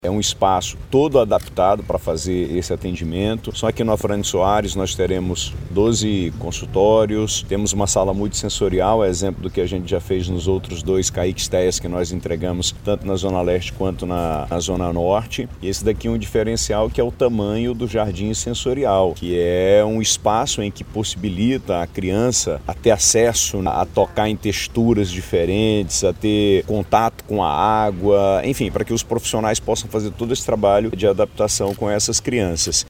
O projeto inclui um jardim sensorial, com cerca de 500 metros quadrados, que permite contato com água e elementos da natureza. A estrutura também terá uma sala com luz, som e diferentes texturas usadas nas terapias, destaca o Governador Wilson Lima.